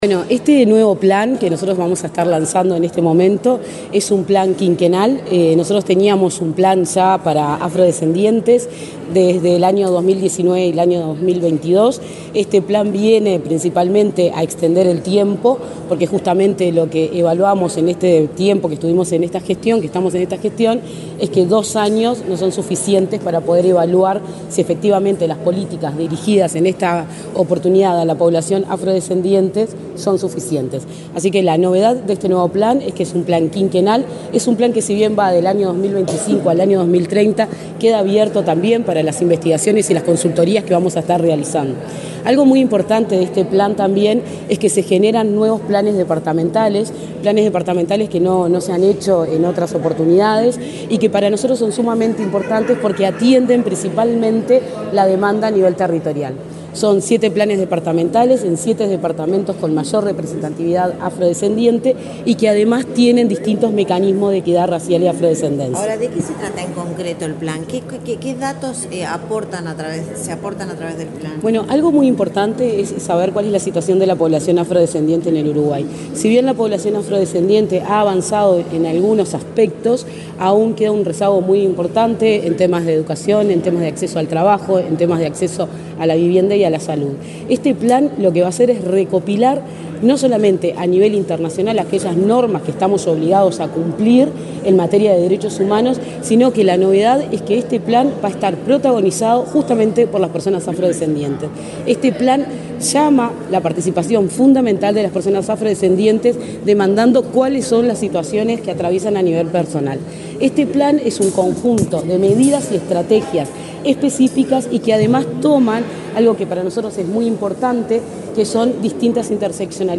Declaraciones de la directora de Promoción Sociocultural del Mides, Rosa Méndez 30/08/2024 Compartir Facebook X Copiar enlace WhatsApp LinkedIn La directora de Promoción Sociocultural del Ministerio de Desarrollo Social (Mides), Rosa Méndez, dialogó con la prensa en la Torre Ejecutiva, antes de participar del lanzamiento del Observatorio para el Seguimiento de la Implementación de Acciones Afirmativas para Personas Afrodescendientes.